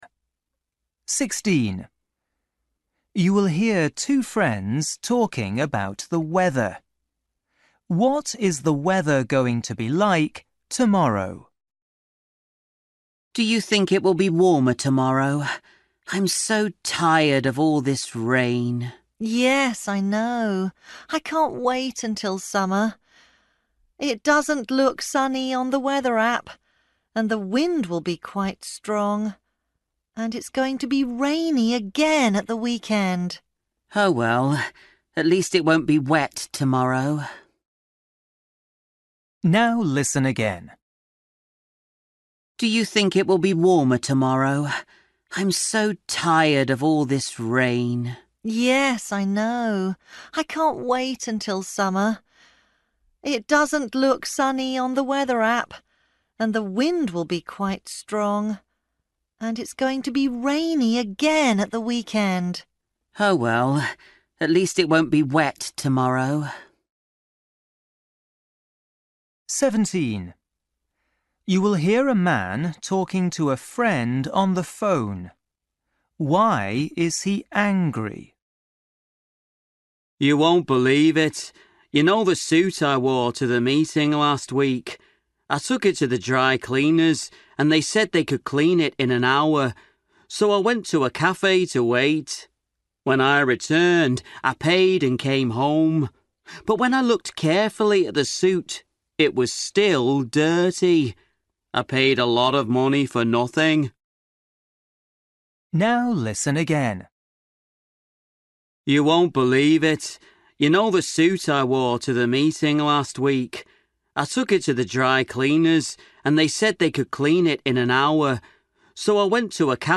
Listening: everyday short conversations
16   You will hear two friends talking about the weather. What is the weather going to be like tomorrow?
17   You will hear a man talking to his friend on the phone. Why is he angry?
18   You will hear a woman talking to her friend about work. Why is she unhappy?